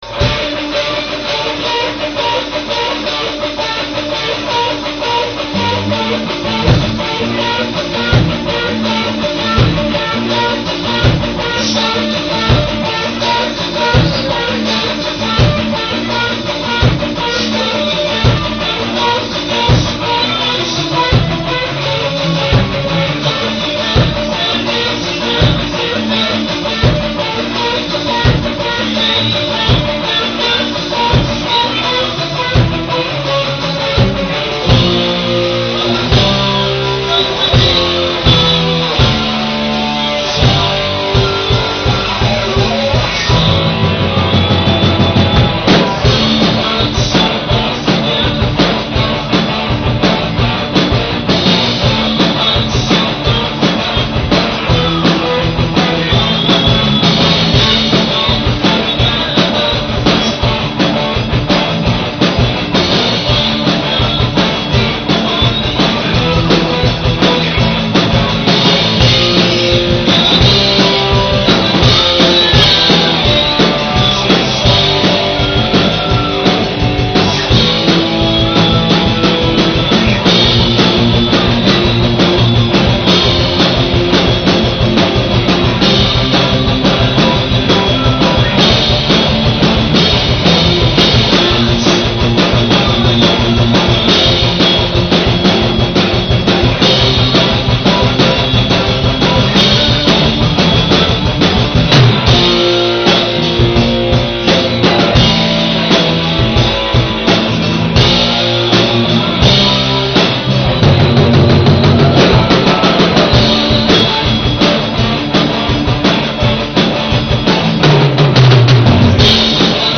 Запись с репетиций